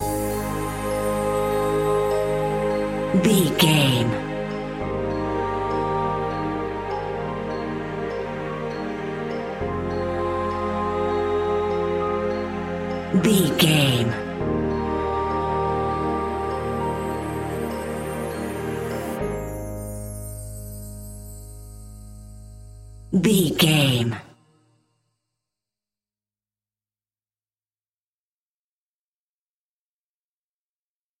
A great piece of royalty free music
Aeolian/Minor
calm
electronic
synths